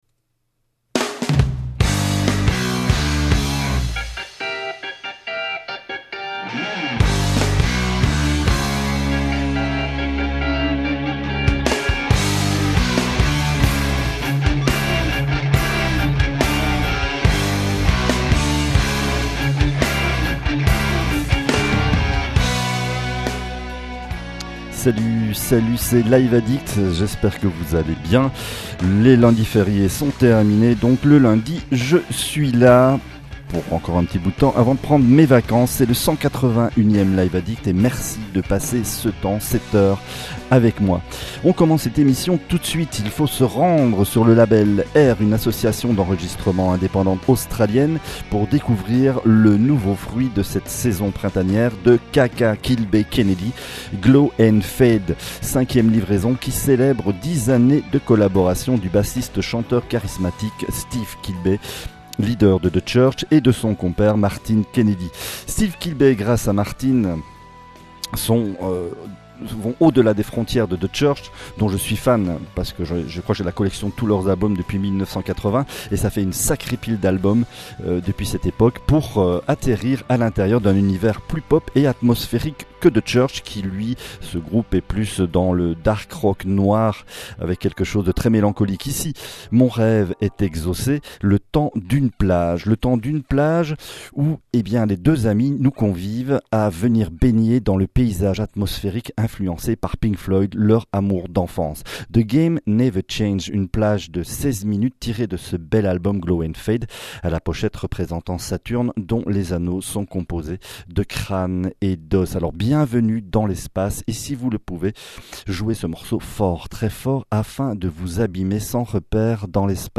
rock , rock progressif